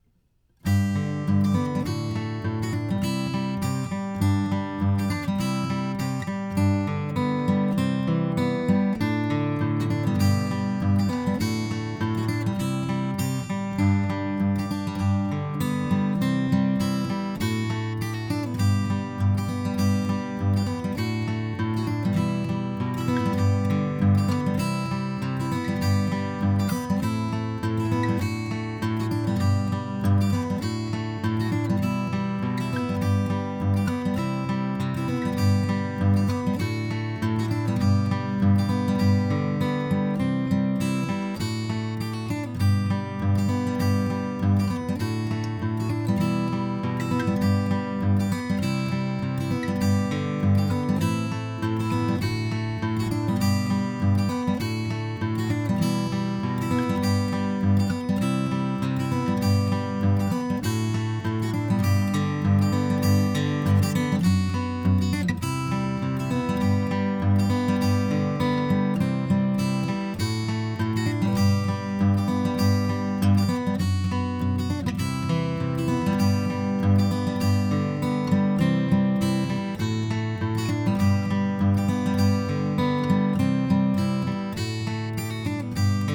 acousticguitar_SSL4KEQ-B.flac